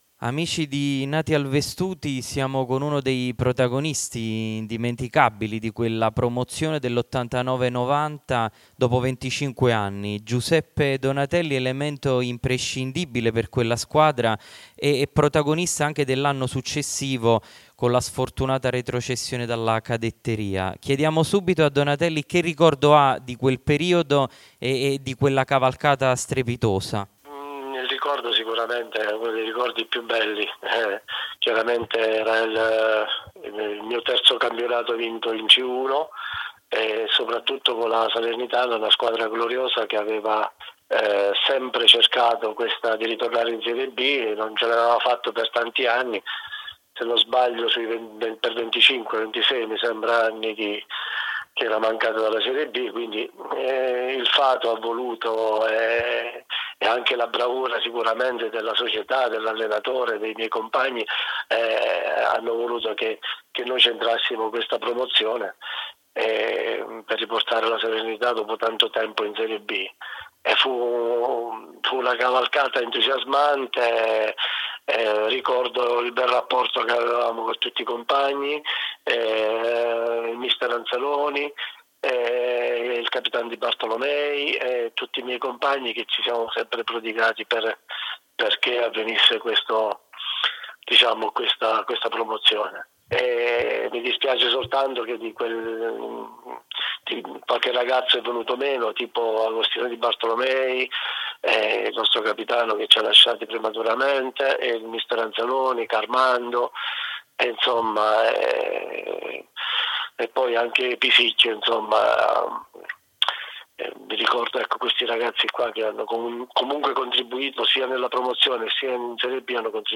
Interviste Lascia un commento